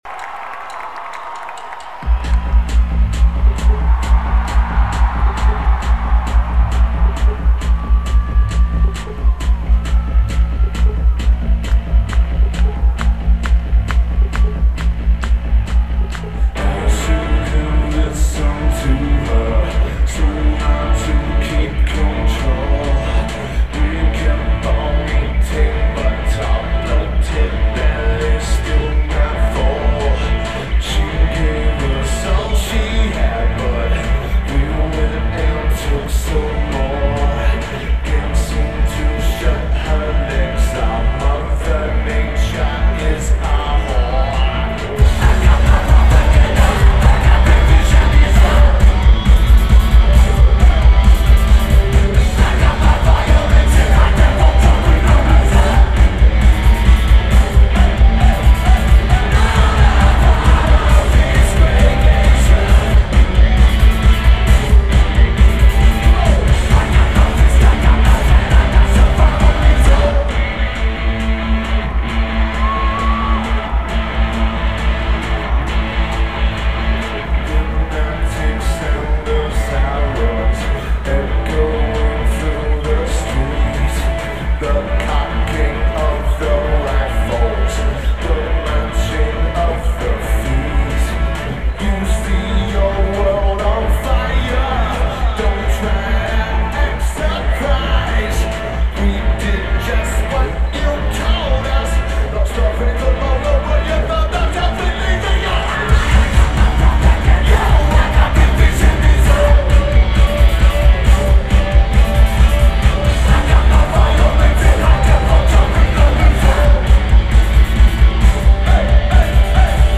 Key Arena
Seattle, WA United States
Lineage: Audio - AUD (CSB's + Sharp MD- MT15)